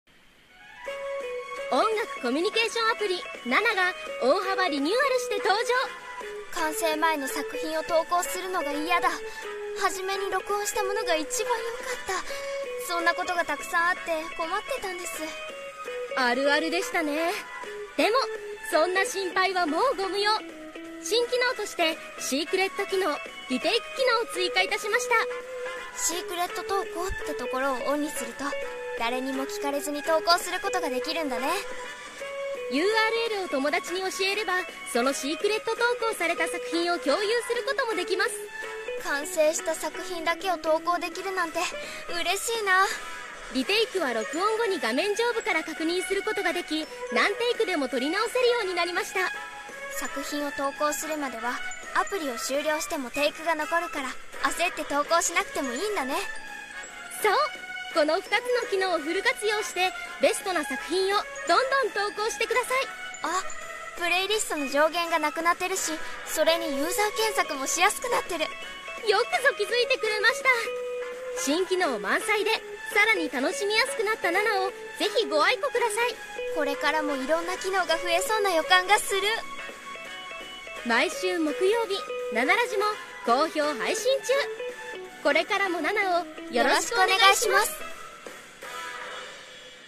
【ナレーション声劇】